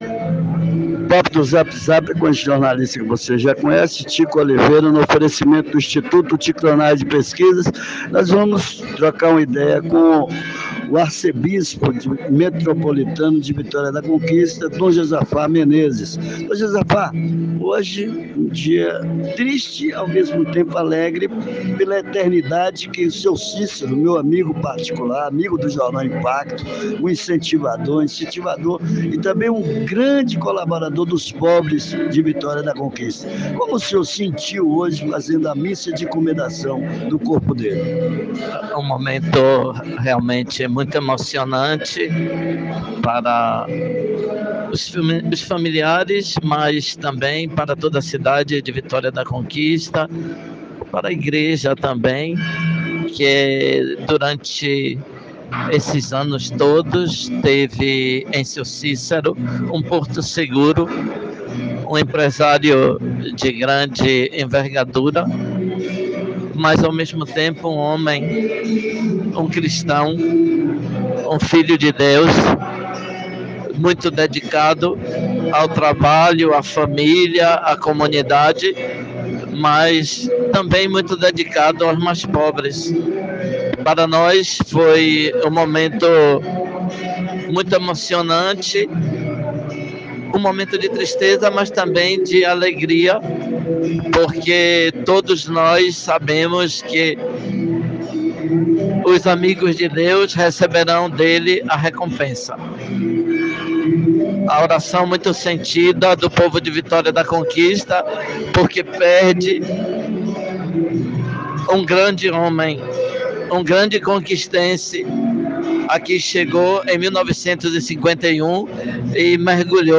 No papo do zap zap, numa troca de ideias impactantes com o jornalista